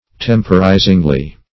temporizingly - definition of temporizingly - synonyms, pronunciation, spelling from Free Dictionary Search Result for " temporizingly" : The Collaborative International Dictionary of English v.0.48: Temporizingly \Tem"po*ri`zing*ly\, adv.
temporizingly.mp3